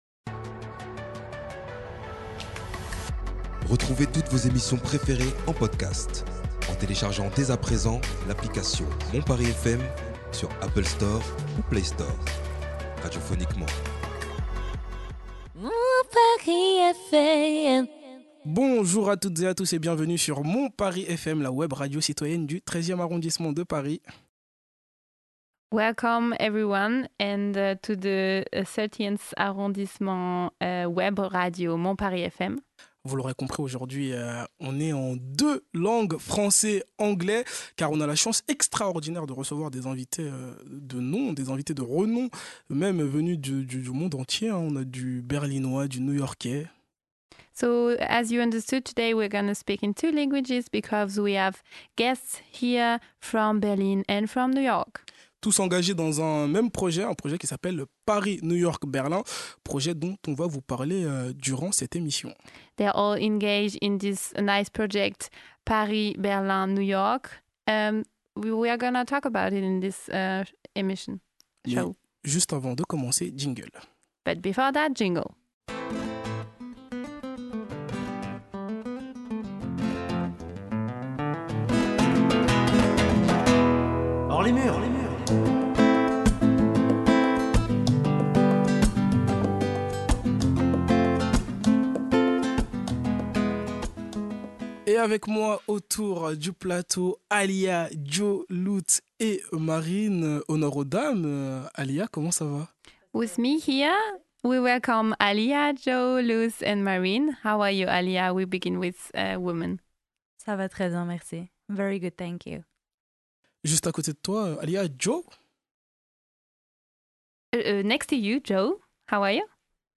On y parle de création collective, d’engagement, de mémoire et de lien social. Une conversation sincère, à l’image d’un projet qui fait du hip-hop un véritable pont artistique et militant.